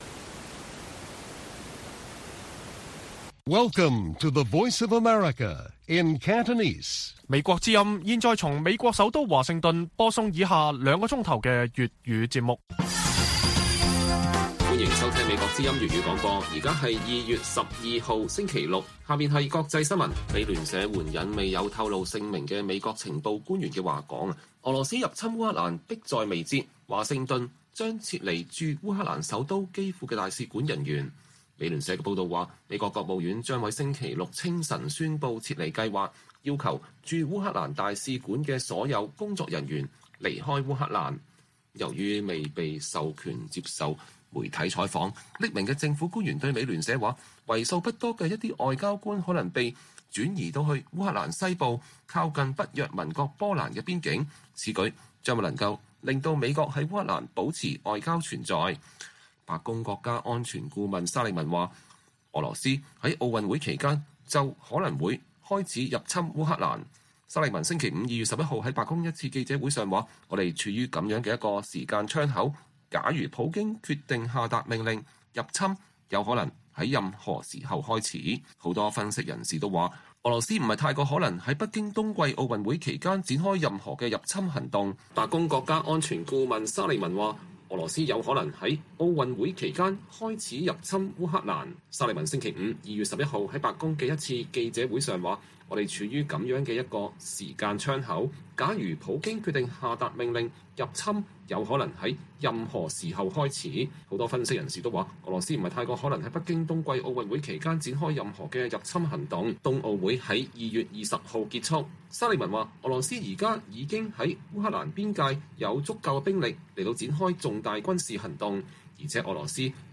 粵語新聞 晚上9-10點 : 美澳日印四國外長承諾深化合作 確保印太不受“脅迫”